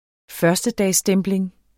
Udtale [ ˈfɶɐ̯sdədasˌsdεmbleŋ ]